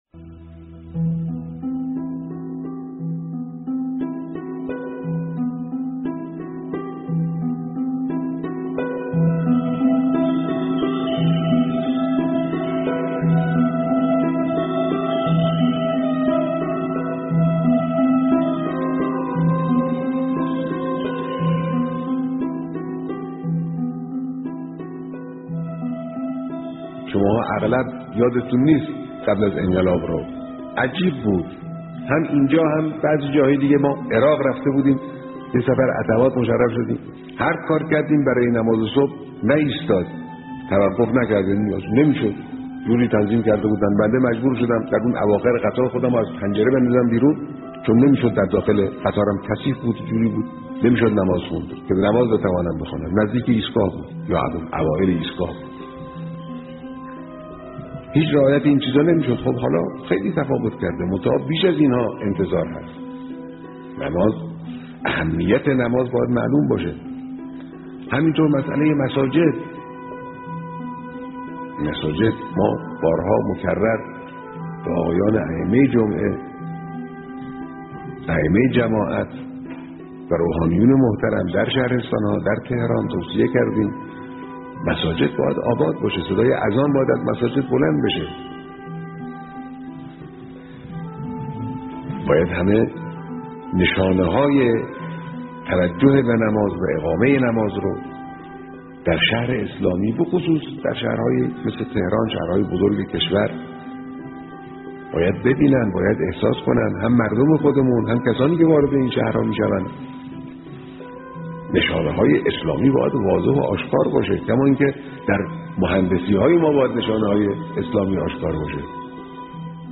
نماز در قطار؛ گزیده‌ای از بیانات رهبر انقلاب در دیدار کارگزاران نماز